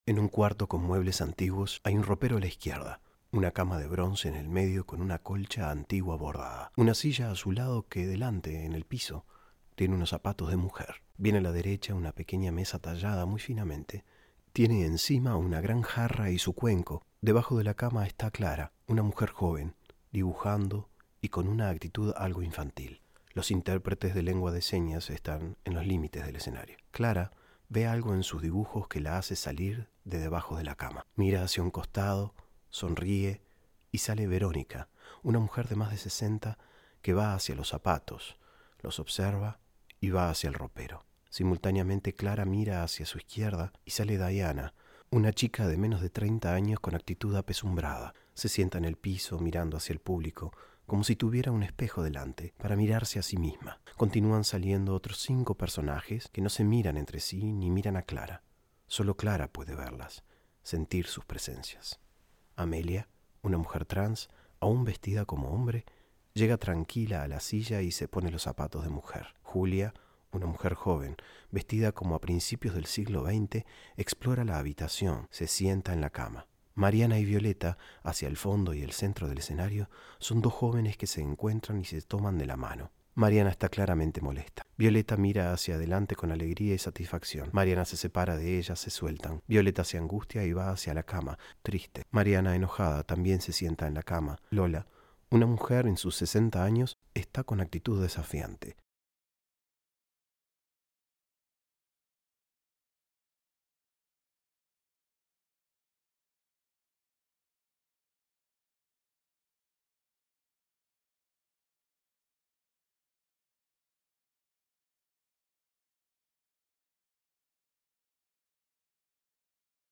Audiodescripcion de la obra: "M: 8 Mujeres Desnudas" – Podcast